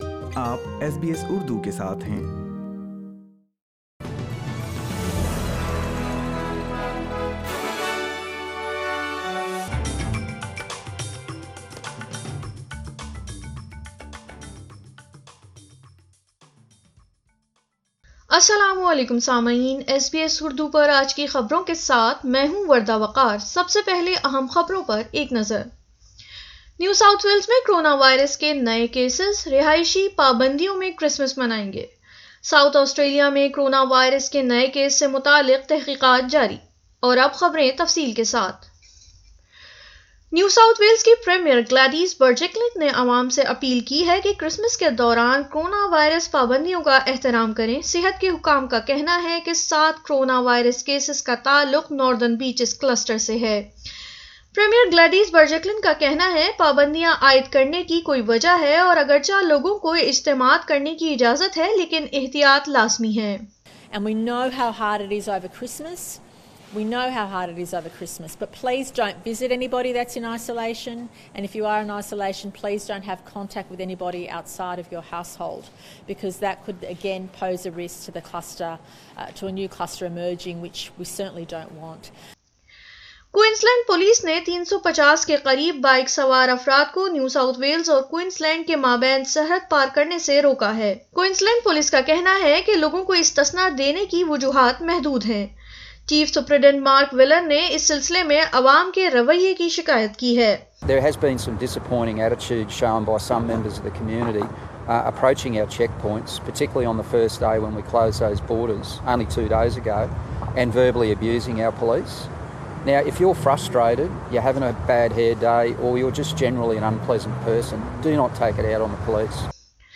اردو خبریں 24 دسمبر 2020